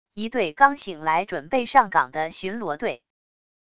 一队刚醒来准备上岗的巡逻队wav音频